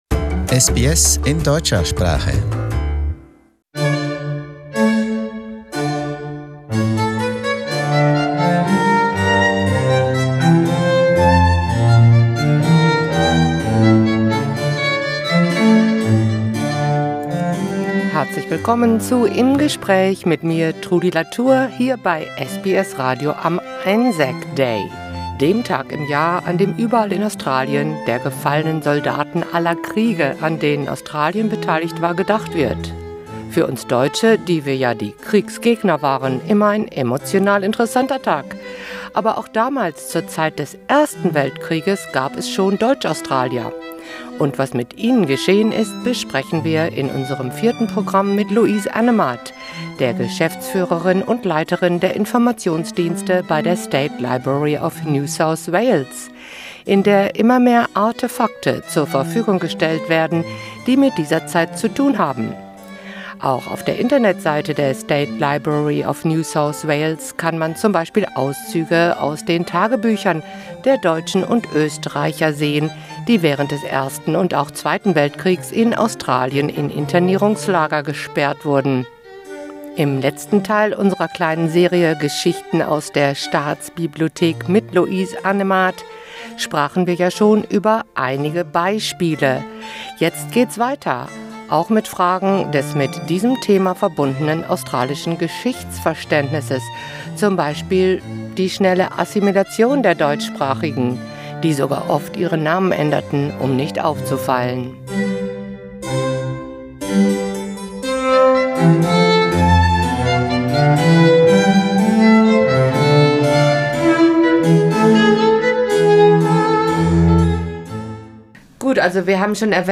In conversation: Why have the German-Australians assimilated so quickly?